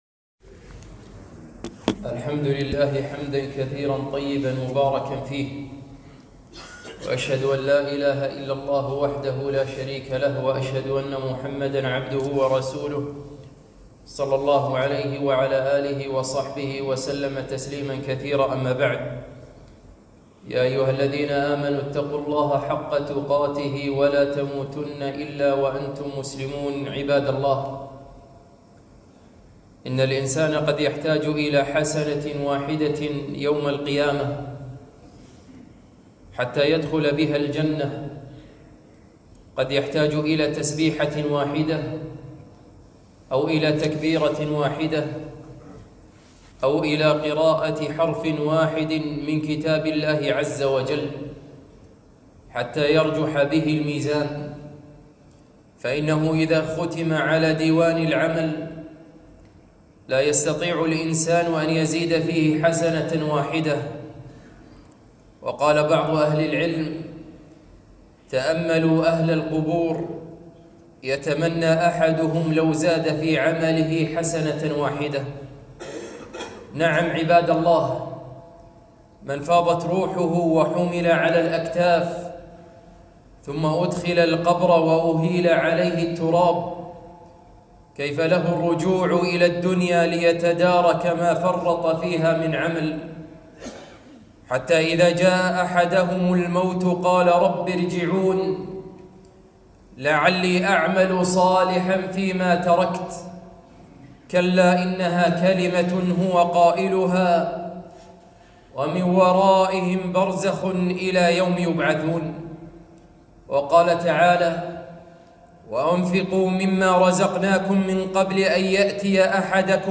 خطبة - أعمال الخير في شهر الخير